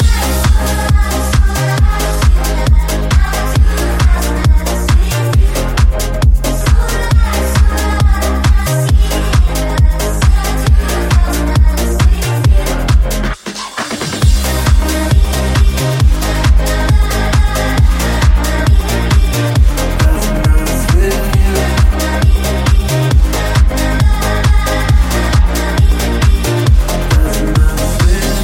Genere: pop,dance,deep,disco,house.groove,latin,hit